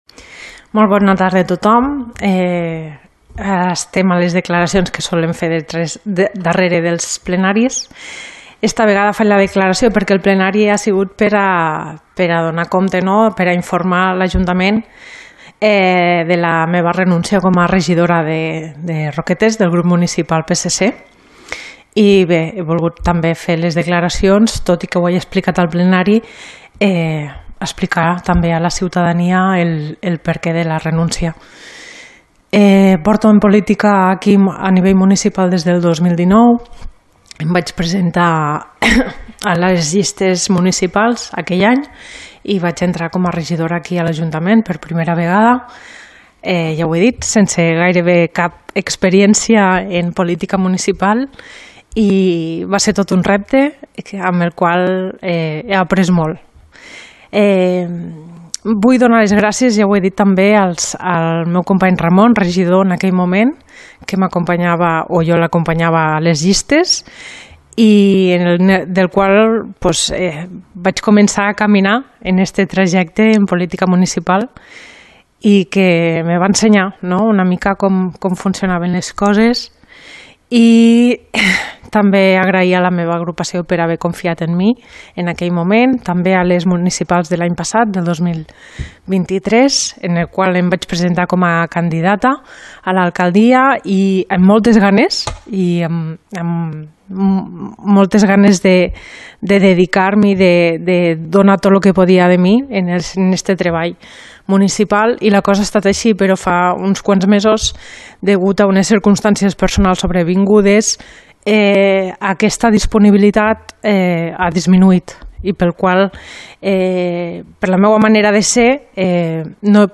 Declaracions de la regidora del PSC-CP Georgina Ebrí, on explica els motius de la renúncia al seu càrrec de regidora | Antena Caro - Roquetes comunicació